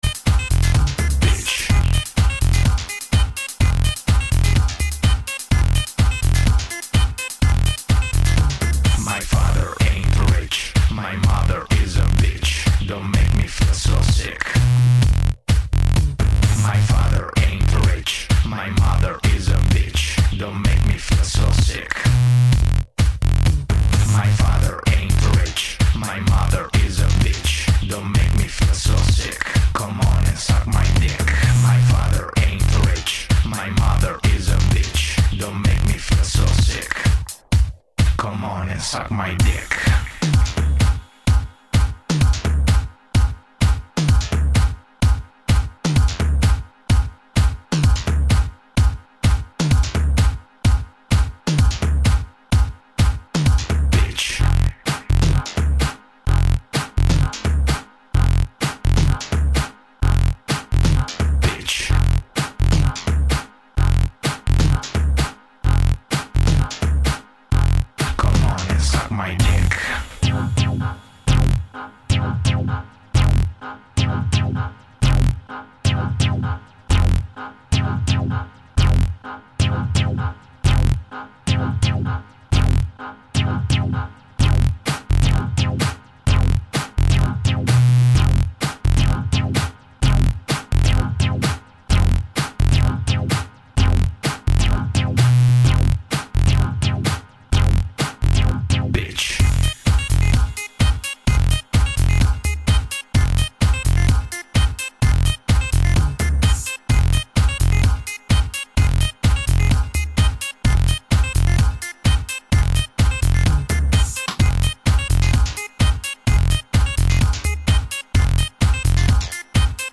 这不是一首普通的的士高音乐， 也不是一首跳舞的HIGH歌， 它是一首让你灵魂飞扬的迷幻乐 它绝对是HI迷必属收藏